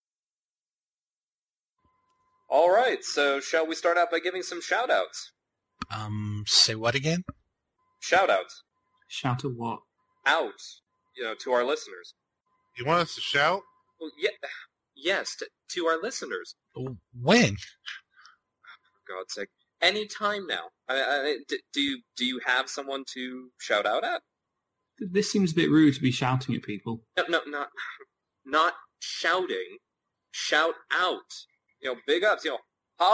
‘ So: if you’re looking for the Home equivalent of a Bill Maher talk show, then grab something to drink, kick back, and listen to these four gents joke around and have some really interesting discussion.